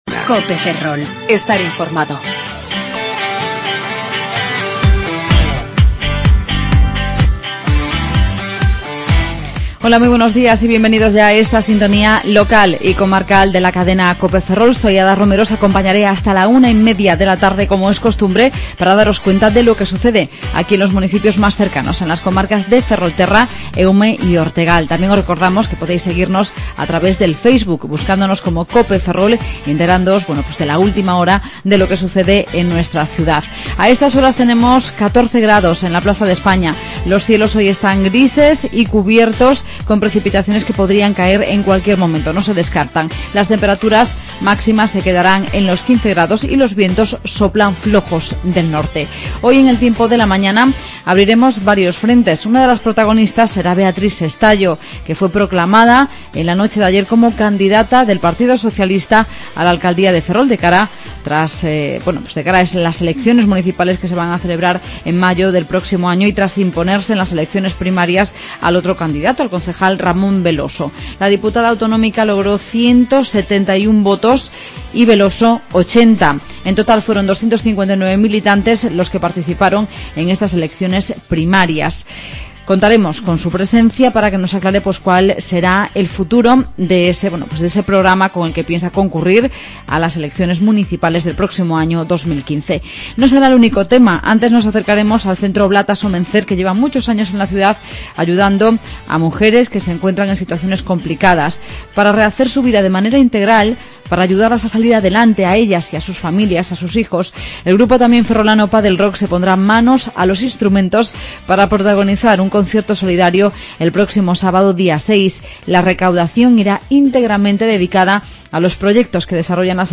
Magazine local y comarcal. Los Padel Rock protagonizarán un concierto solidario el próximo sábado día 6 de diciembre en el Jofre a favor del centro As Oblatas- O Mencer.